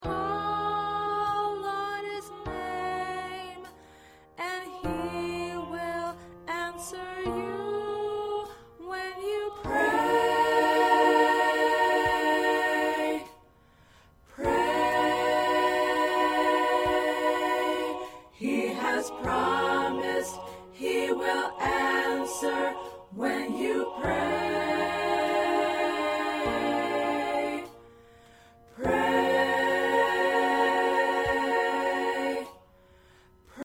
All parts combined.